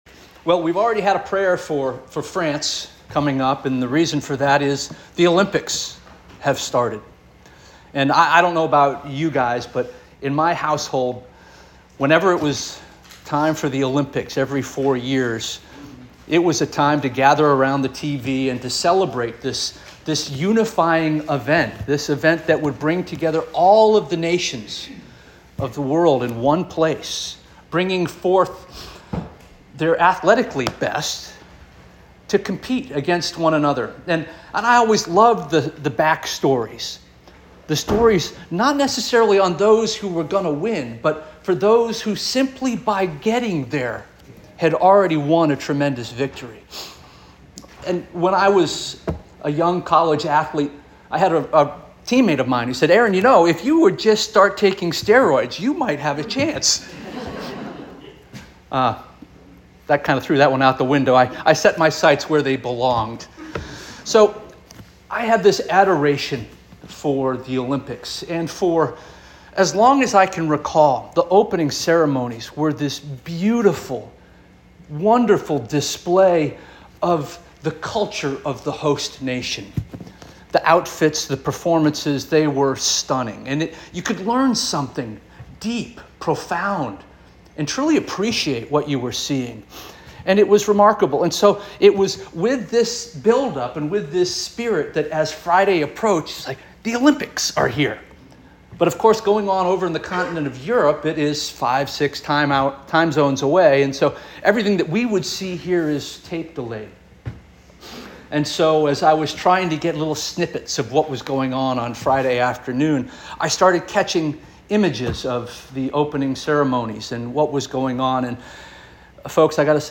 July 28 2024 Sermon - First Union African Baptist Church